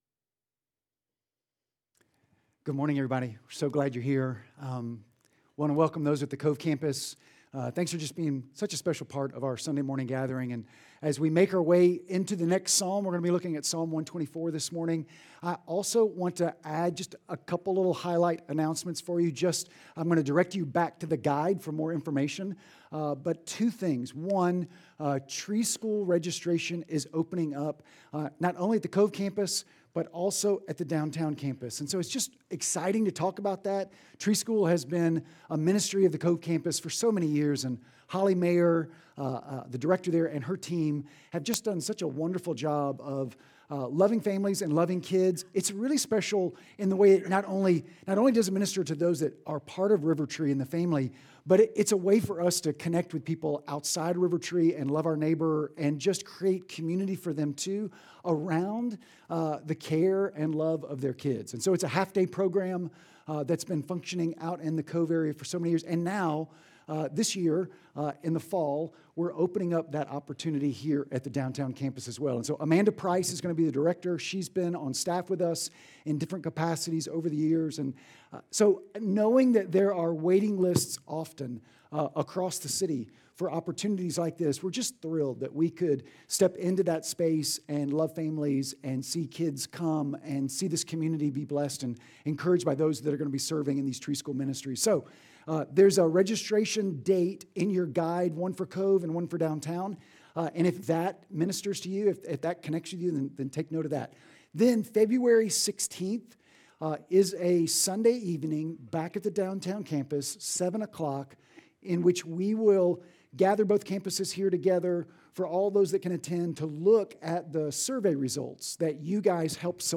Sermon NOtes Sermon Audio…